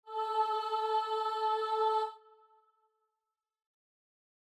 Starting Note Stop Listen Stop Master Sight Singing Become a SightReadingMastery member and get unlimited, professionally-composed sight reading, live help from music teachers and much more.
starting_note.mp3